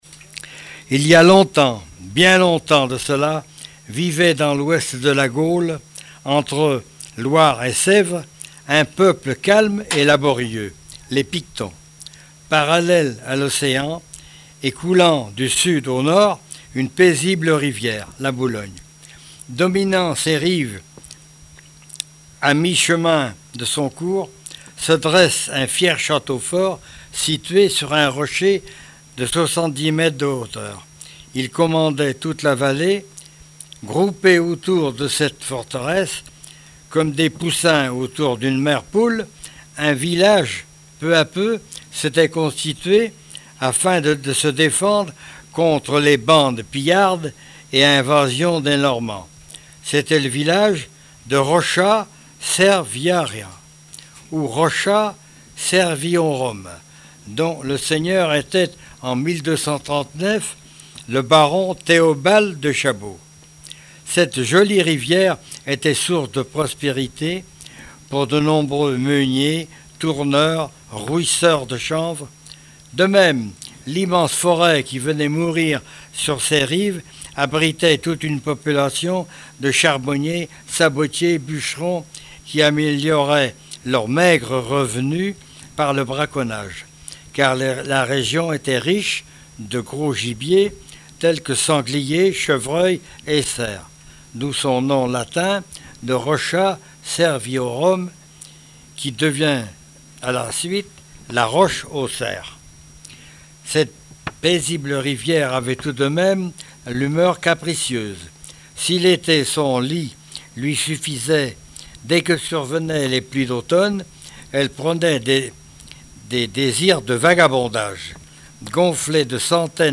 Mémoires et Patrimoines vivants - RaddO est une base de données d'archives iconographiques et sonores.
Genre conte
Catégorie Récit